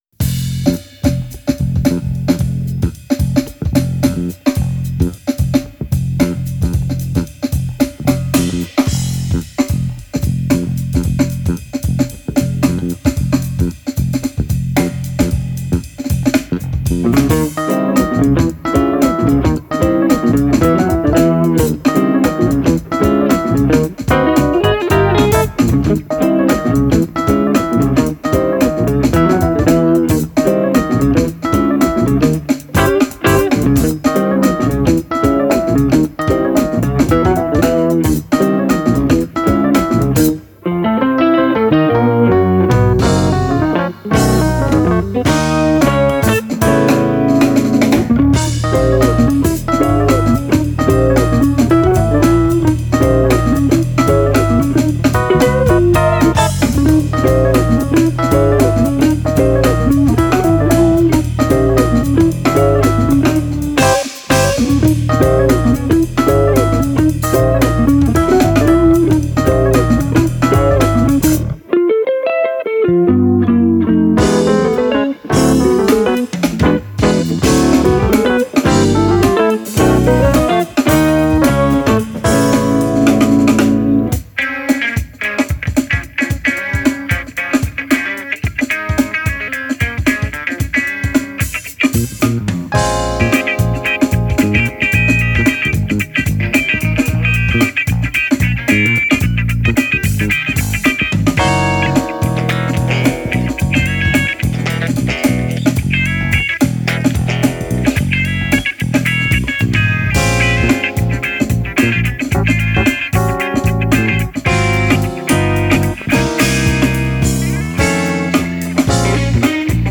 [ fusion / jazz / funk / quartet ]